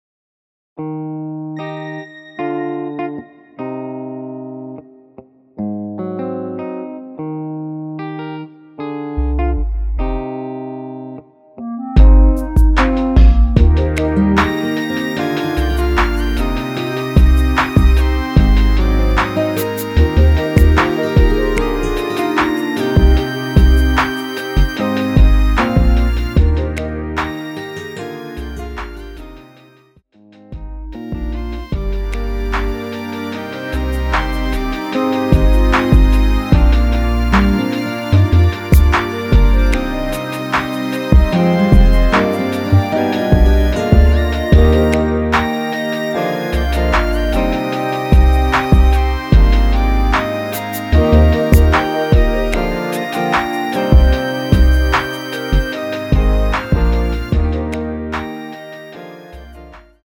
원키 멜로디 포함된 MR 입니다.(미리듣기 참조)
Eb
앞부분30초, 뒷부분30초씩 편집해서 올려 드리고 있습니다.
중간에 음이 끈어지고 다시 나오는 이유는